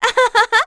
Juno-Vox_Happy2.wav